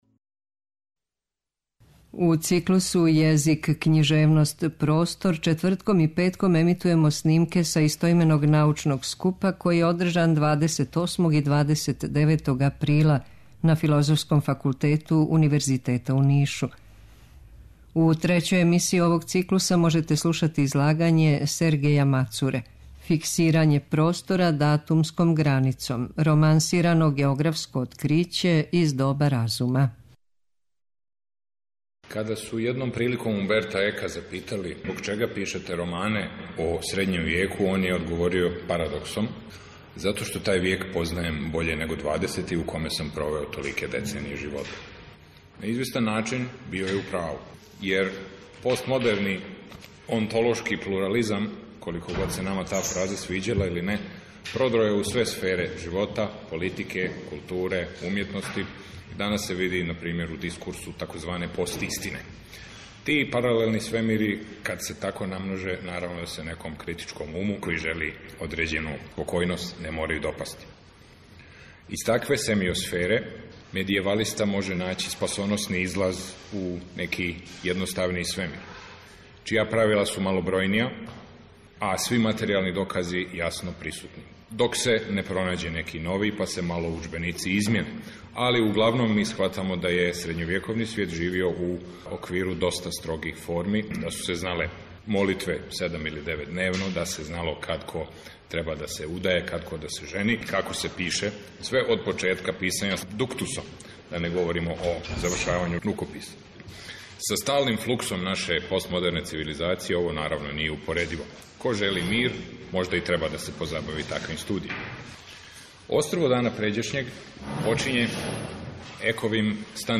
У циклусу ЈЕЗИК, КЊИЖЕВНОСТ, ПРОСТОР четвртком и петком ћемо емитовати снимке са истименог научног скупа, који је ордржан 28. и 29. априла на Филозофском факултету Универзитета у Нишу.
Научни скупoви